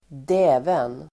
Ladda ner uttalet
Uttal: [²d'ä:ven]